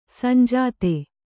♪ sanjāte